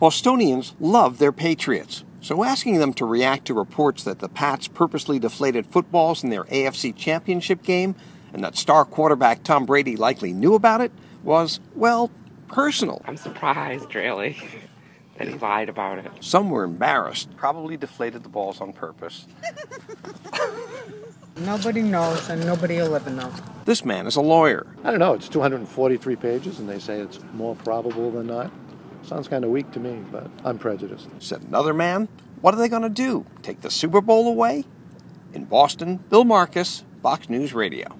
(BOSTON) MAY 6 – BOSTONIANS ARE REACTING TO REPORTS THAT THEIR PATRIOTS LIKELY INTENTIONALLY DEFLATED FOOTBALLS IN A PLAYOFF GAME.